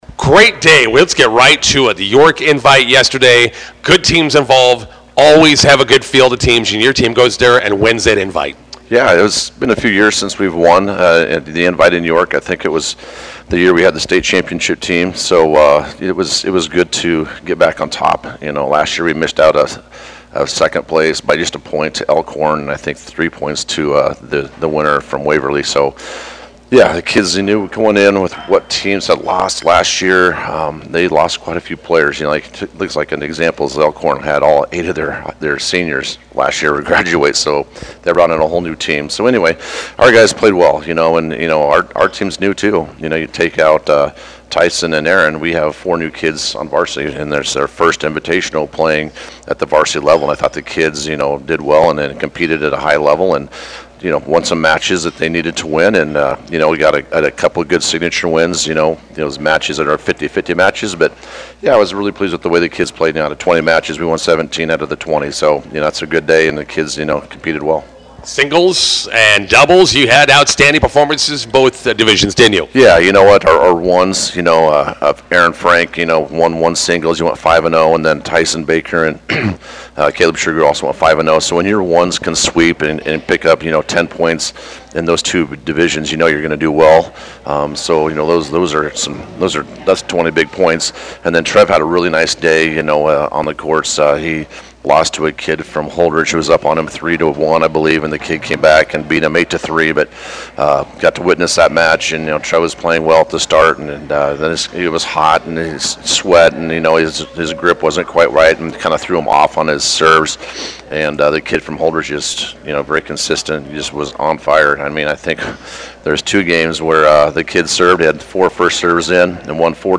INTERVIEW: Bison boys tennis win York Invite on Friday.